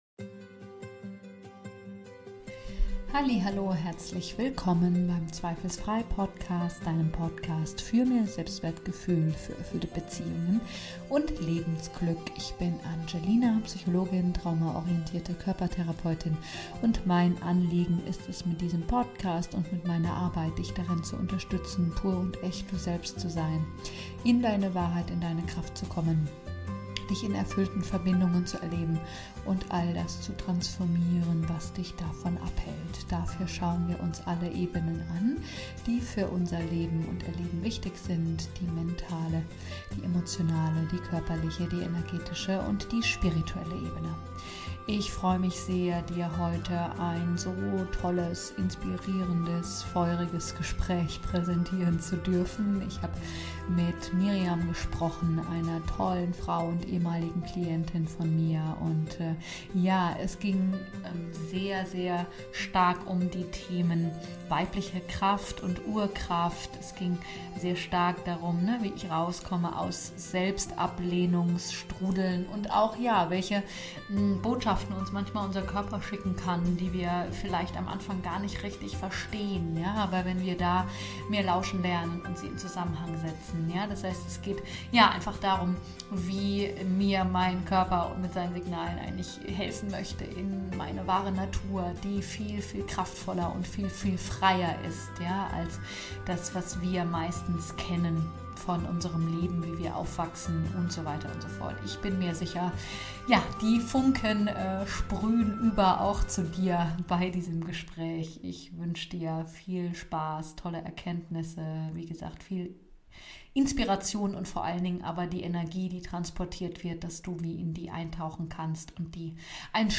Von patriarchalischen Gefängnissen & Wunden in echte Weiblichkeit & Urkraft- Kundinnen Power Talk